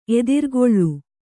♪ edirgoḷḷu